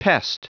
Prononciation du mot pest en anglais (fichier audio)
Prononciation du mot : pest